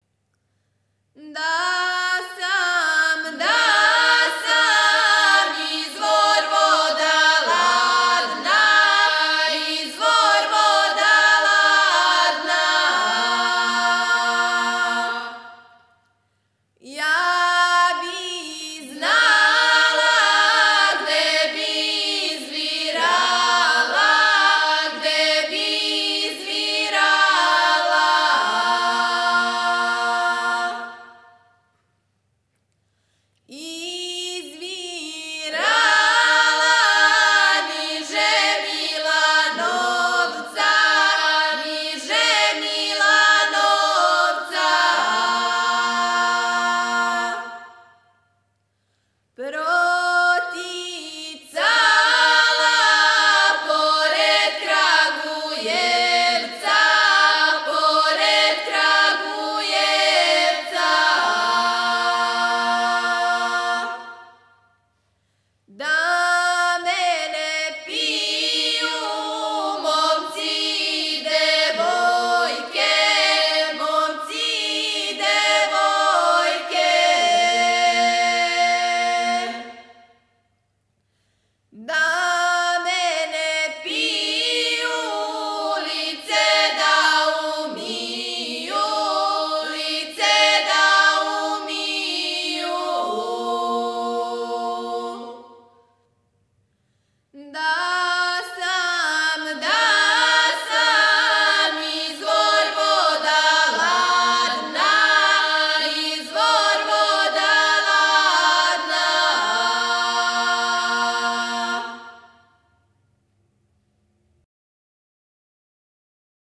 Порекло песме: Североисточна Србија
Начин певања: На бас